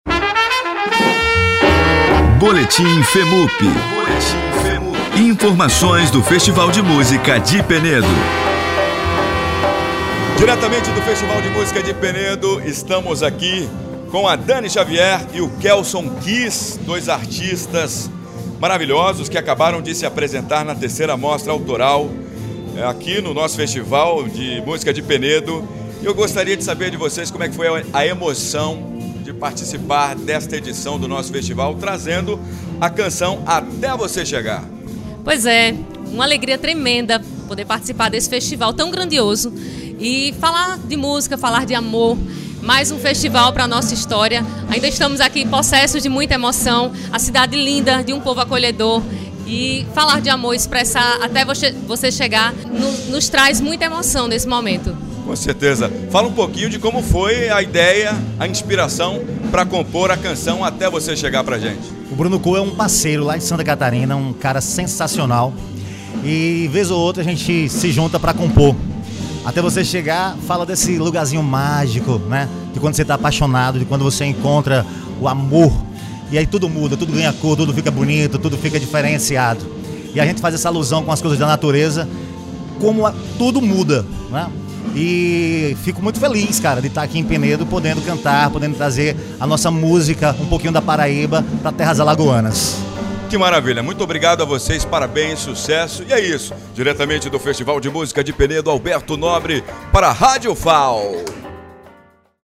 Entrevista com os autores da música "Até você chegar"